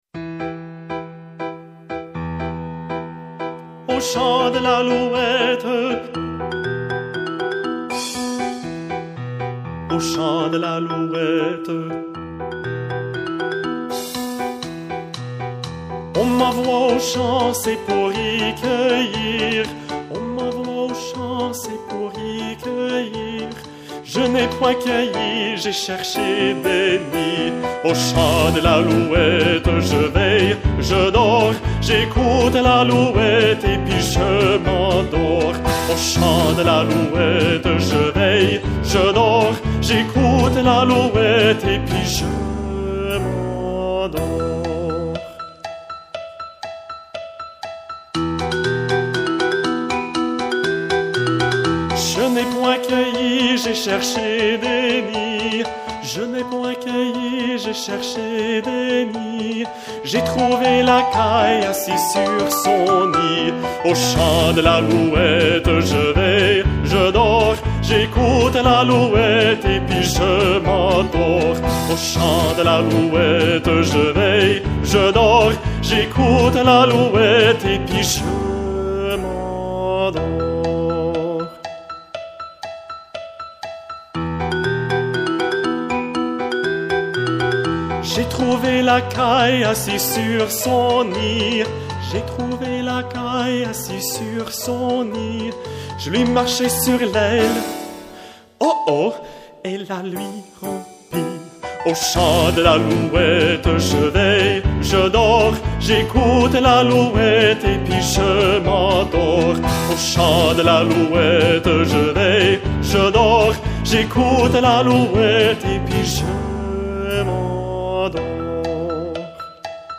Chant thème des choralies 2014